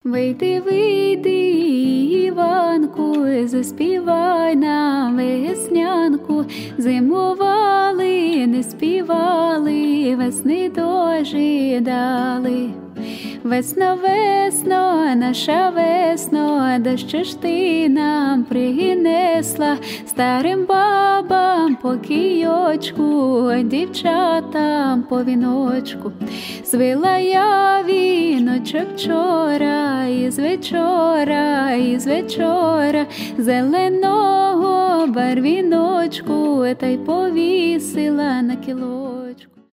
cover , фолк , поп